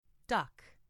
duck.mp3